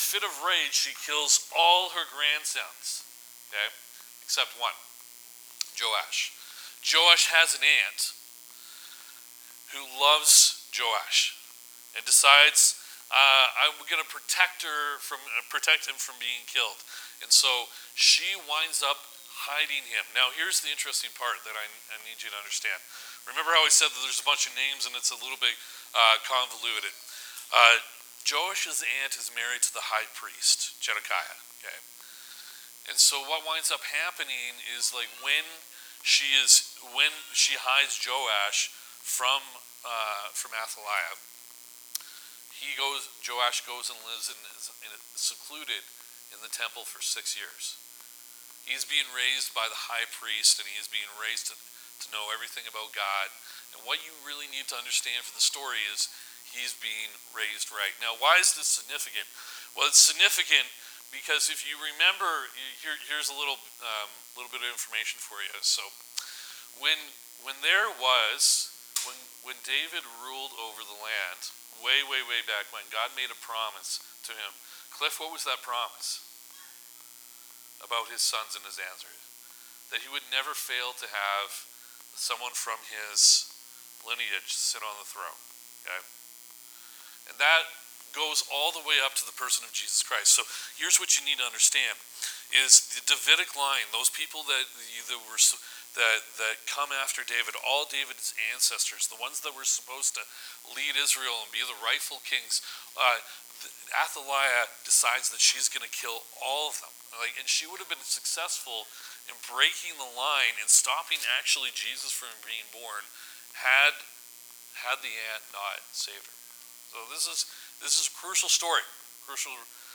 Oct 12 Sermon